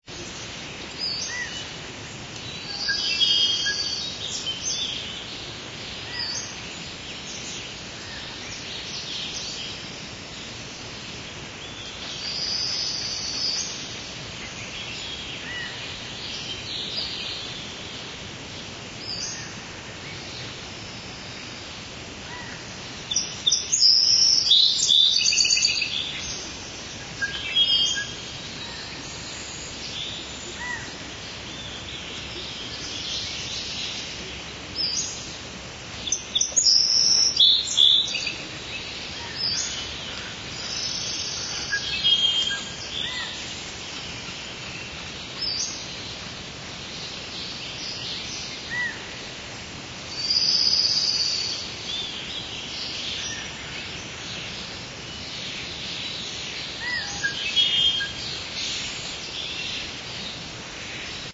rainforest_ambience.ogg